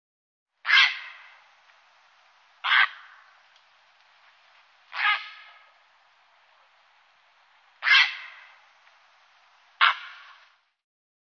Graureiher_Ruf.mp3